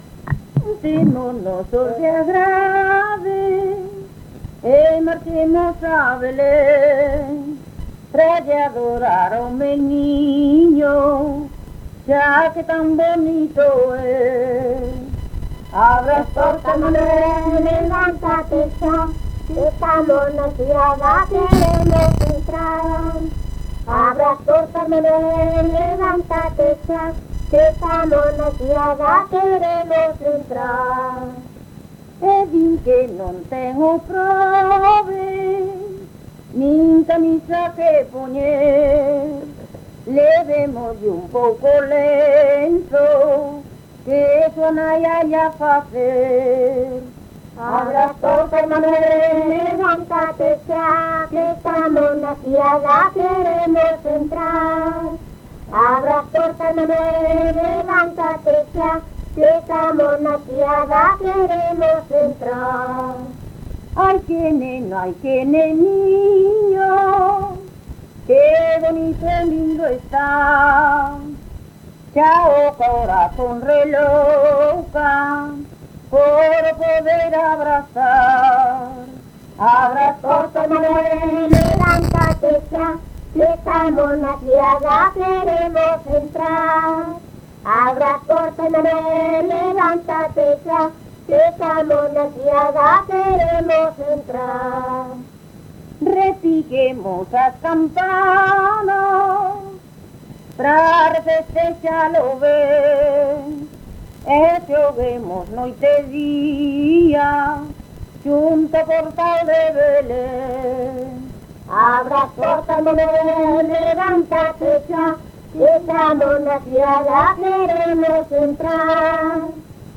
Canto de Nadal: Abre as portas Manuel
Tipo de rexistro: Musical
Áreas de coñecemento: LITERATURA E DITOS POPULARES > Cantos narrativos
Soporte orixinal: Casete
Instrumentación: Voz
Instrumentos: Voz feminina